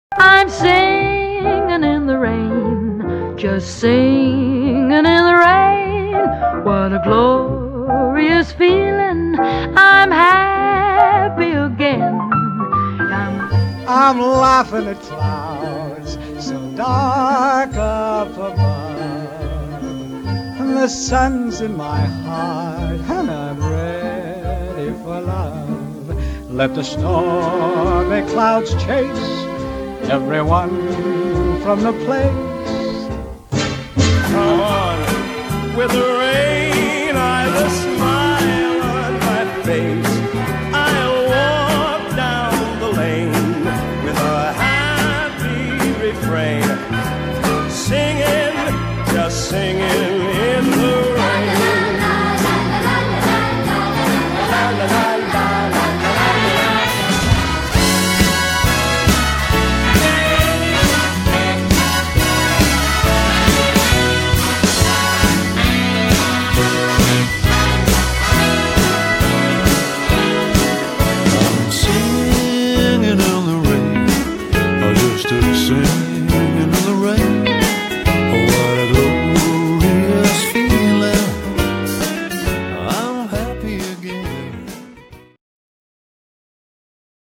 Un medley maison de versions